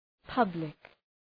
Προφορά
{‘pʌblık}